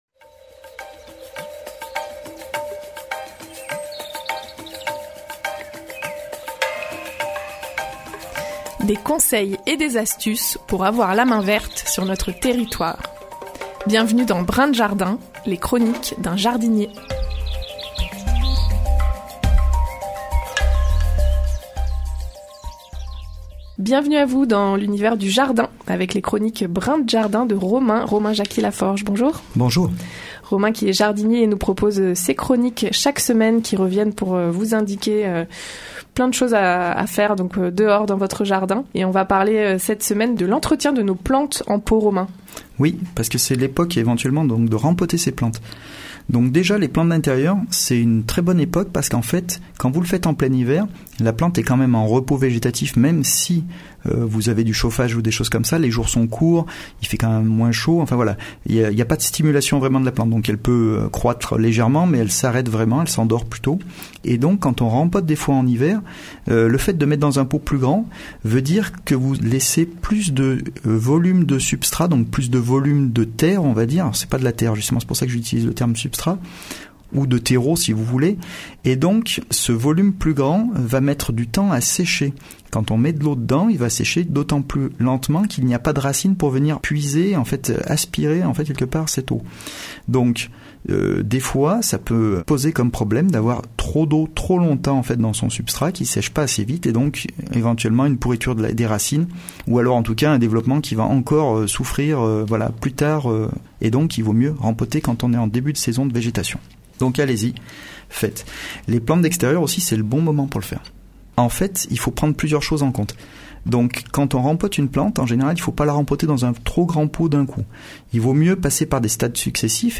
La chronique jardin hebdomadaire sur les ondes de Radio Royans Vercors